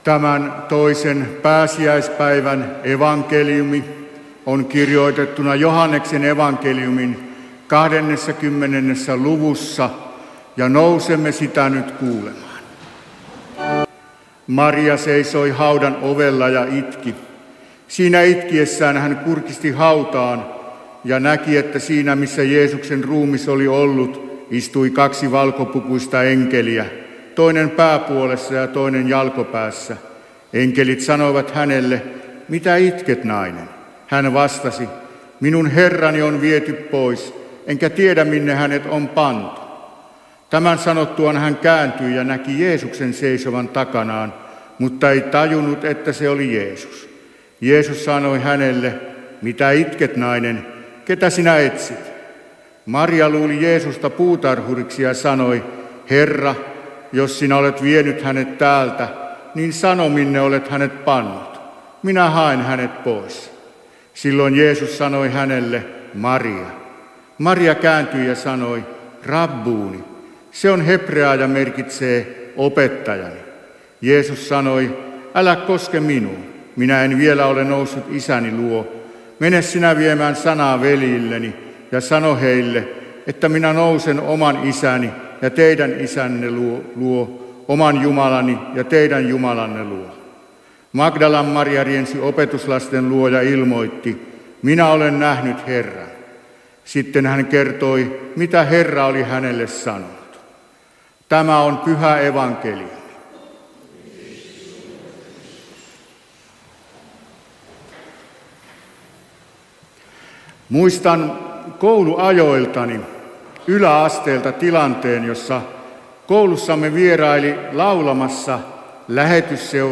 Vetelissä II pääsiäispäivänä Tekstinä Joh. 20:11–18